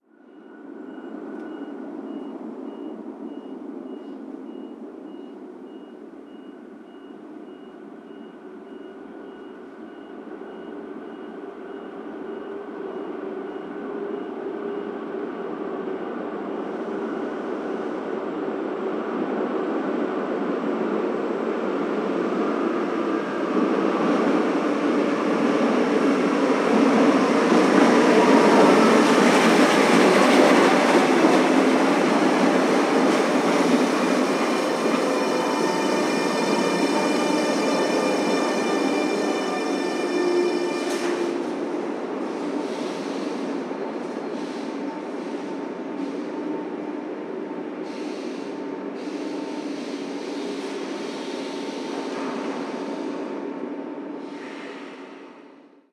Tren llegando a una estación
estación de ferrocarril
tren
Sonidos: Transportes